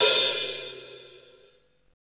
Index of /90_sSampleCDs/Roland L-CD701/KIT_Drum Kits 4/KIT_Attack Kit
HAT ROCK H0B.wav